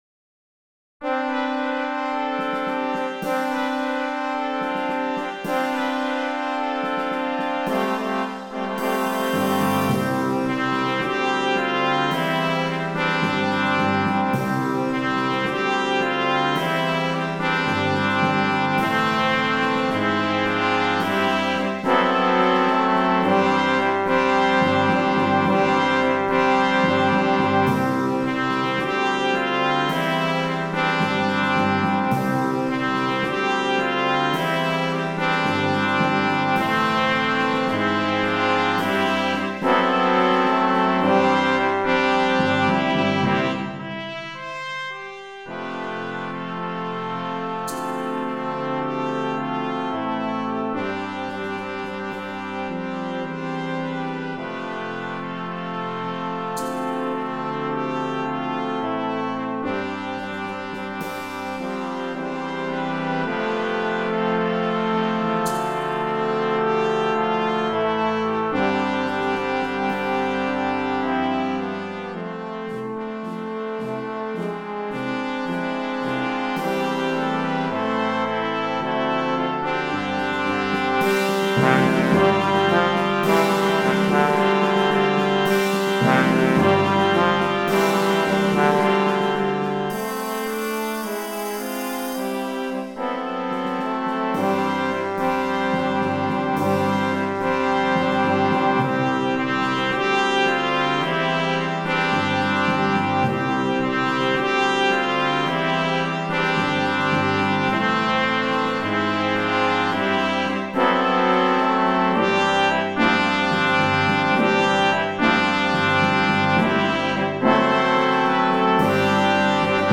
2. Orquesta de viento juvenil (flex)
8 Partes y Percusión
Música ligera
muy fácil - fácil
Particella 1 en do (8va): Flauta
Particella 8 en mib: Tuba mib, Saxofón Barítono mib
Percusión